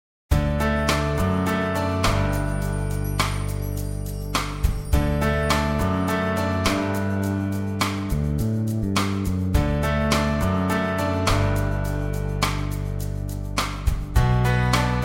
Acoustic, Instrumental